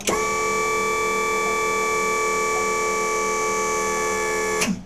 Index of /90_sSampleCDs/E-MU Producer Series Vol. 3 – Hollywood Sound Effects/Water/Cassette Door
CASSETTE 00R.wav